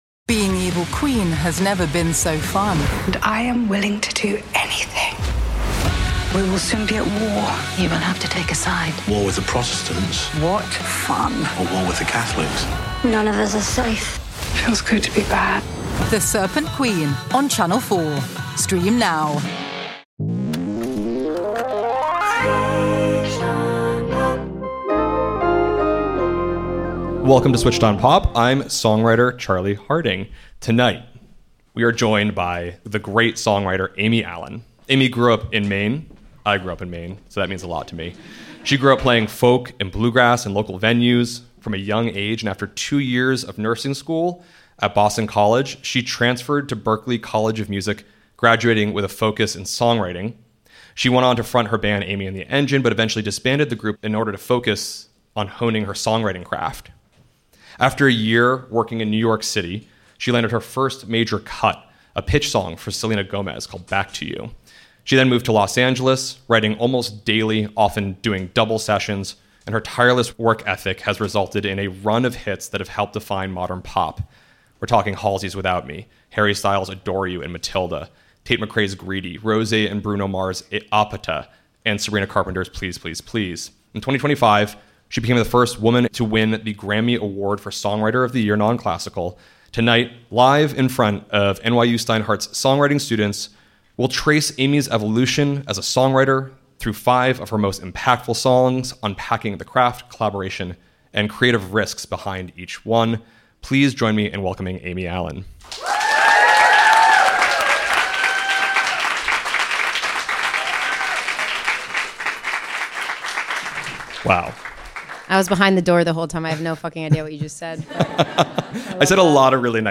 Grammy-winning songwriter Amy Allen joins NYU Steinhardt students live to trace her path from early pitch songs to co-writing some of the decade's defining hits. She explains why Halsey's "Without Me" needed an extended chorus but no pre-made chord loops, how Harry Styles' "Matilda" required character-driven writing for emotional safety, and what made the hypnotic groove of Tate McRae's "Greedy" demand a rare third verse.